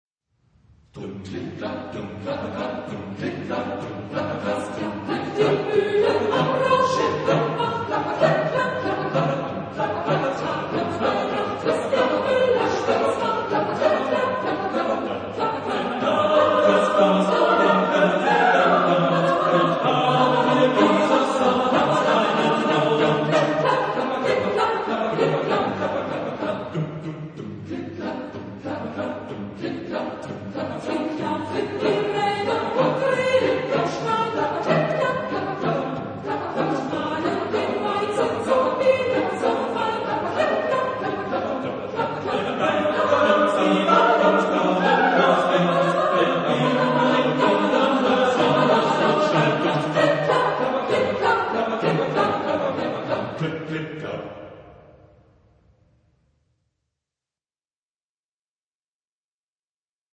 Genre-Stil-Form: Liedsatz ; Volkslied ; weltlich
Chorgattung: SATBB  (5 gemischter Chor Stimmen )
Tonart(en): G-Dur
von Kölner Kantorei gesungen